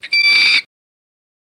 Mink Loud Screech